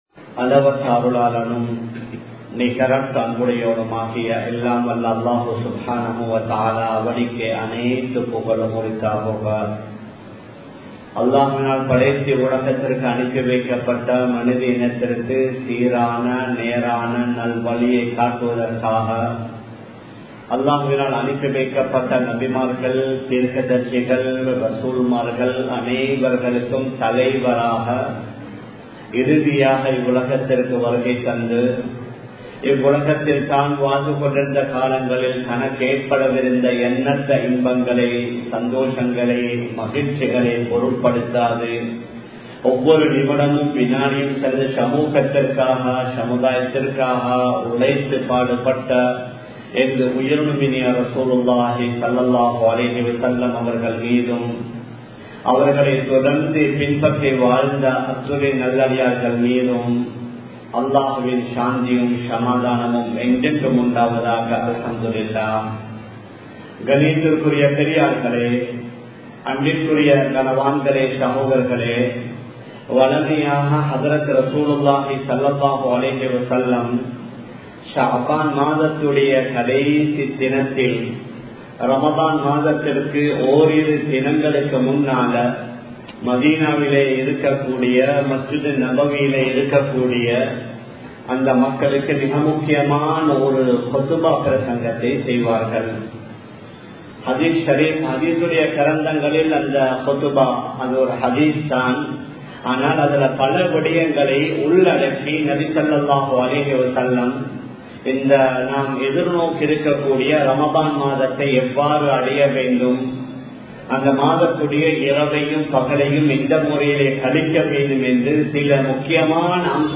ரமழானுக்கு இவ்வளவு சிறப்பா? | Audio Bayans | All Ceylon Muslim Youth Community | Addalaichenai